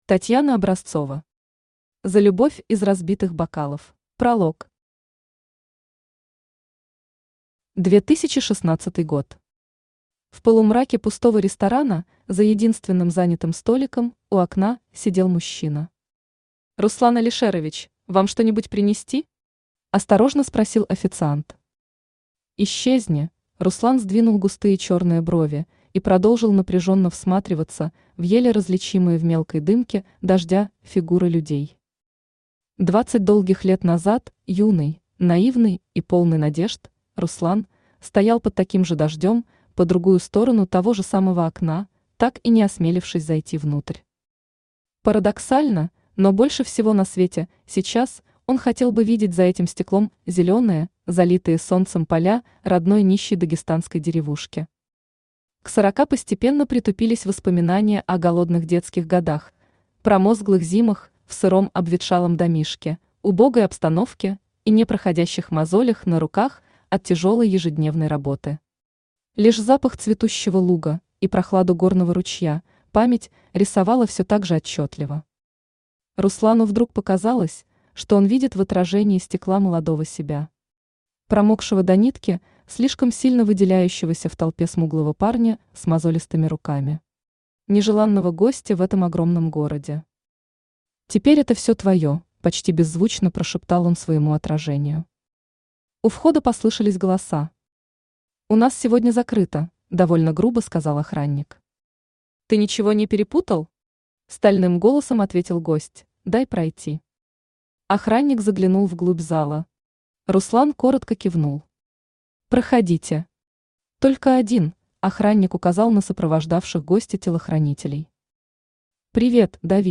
Аудиокнига За любовь из разбитых бокалов | Библиотека аудиокниг
Aудиокнига За любовь из разбитых бокалов Автор Татьяна Образцова Читает аудиокнигу Авточтец ЛитРес.